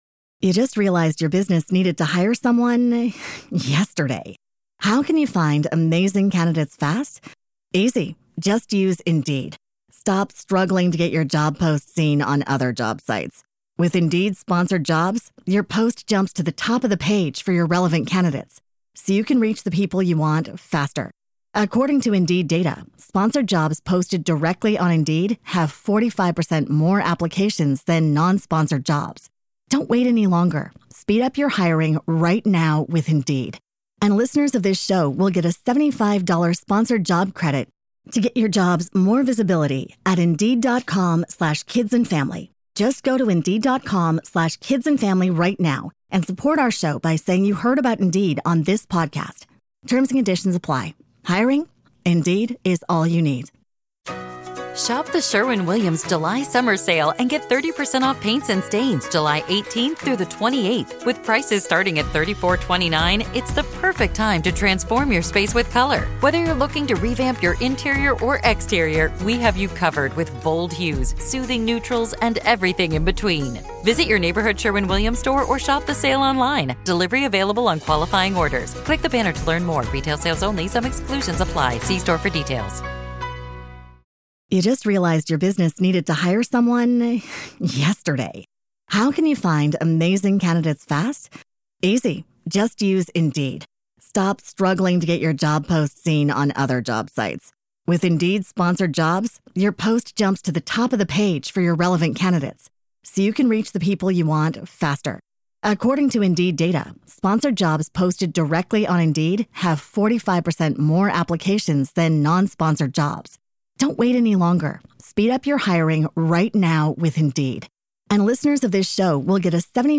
Ghost stories, weird history, and plenty of spine-chilling laughs—what more could you ask for? This is Part Two of our conversation.